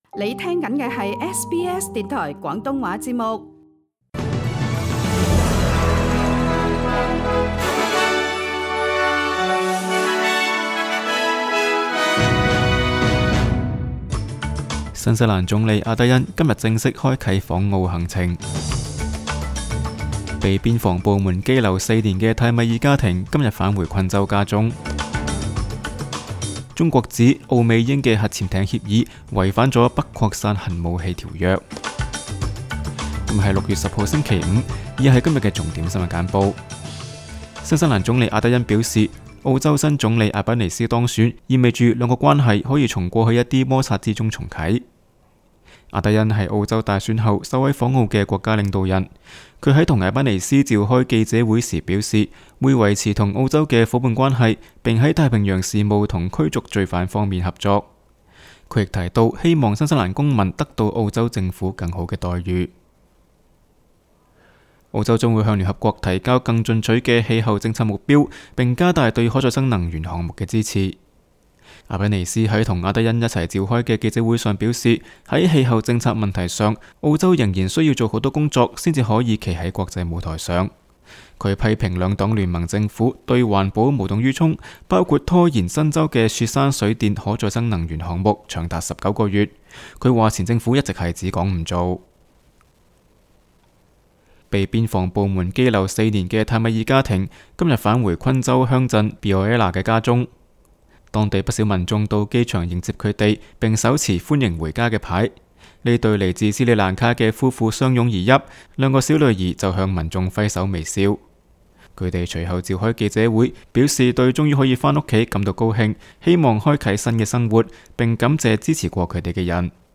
SBS 新闻简报（6月10日）